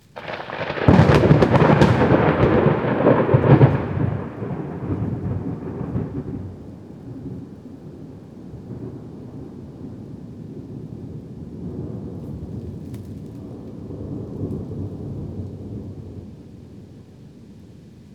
thunder-5.mp3